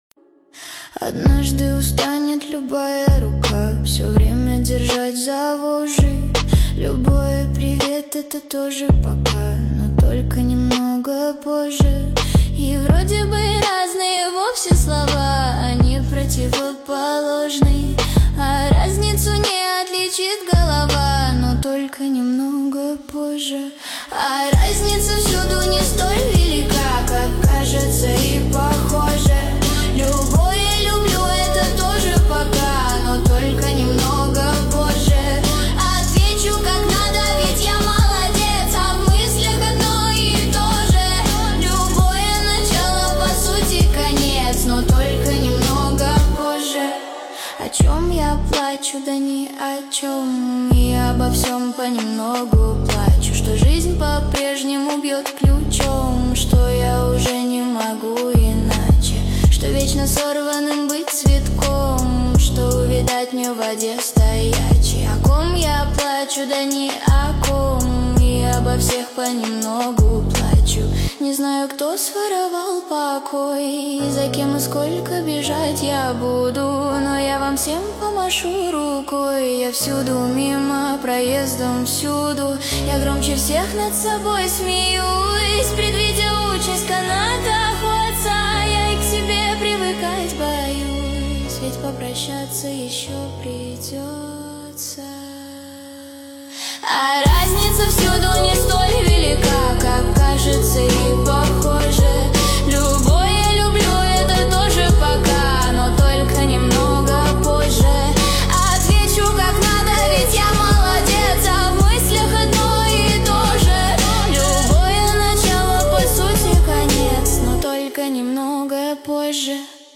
Качество: 320 kbps, stereo
Нейросеть Песни 2025, Стихи